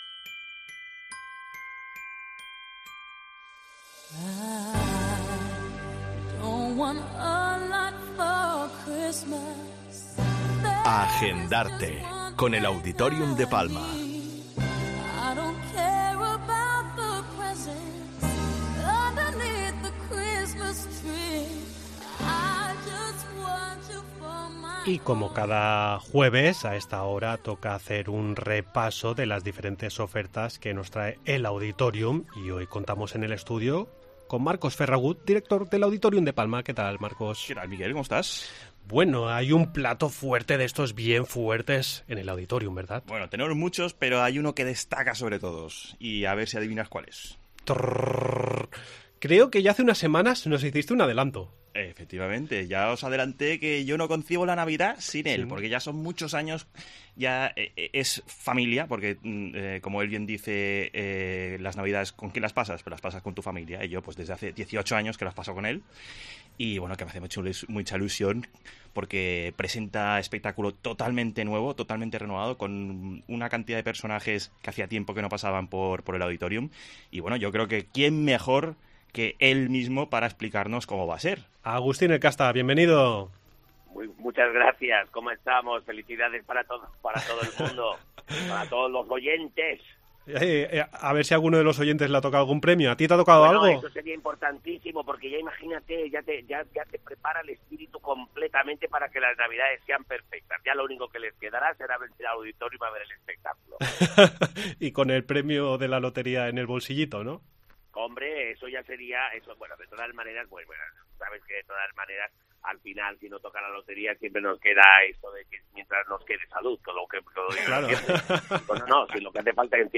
Entrevista en 'La Mañana en COPE Más Mallorca', jueves 22 de diciembre de 2022